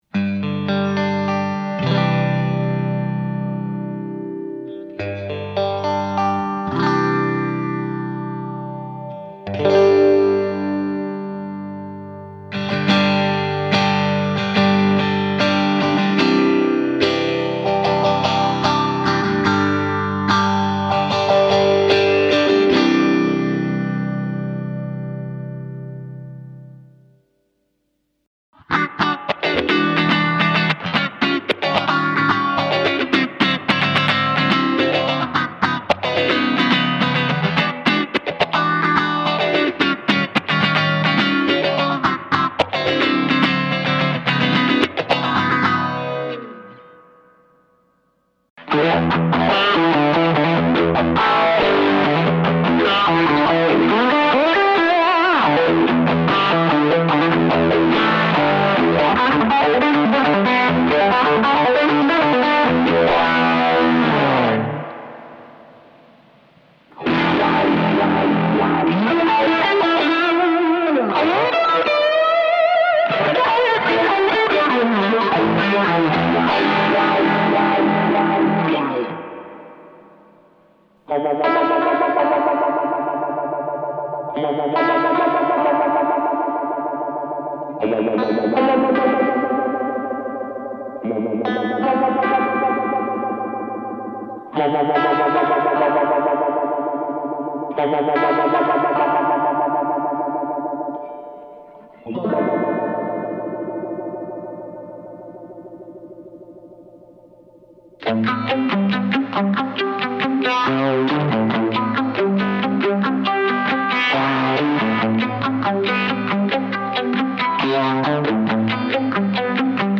レトロヴァイブ・“ウォーム”フェイザー（Montreux Retrovibe Phaser “Warm”）は、1970年代中期に多くのギタリストのペダルボードに搭載されたフェイザーの始祖である１台、スクリプトロゴ期のMXR Phase 45をターゲットにしたペダルです。“Speed”１ノブによるシンプルなコントロールと、姉妹機のPhase 90に比べて控えめで爽やかな効果が特徴です。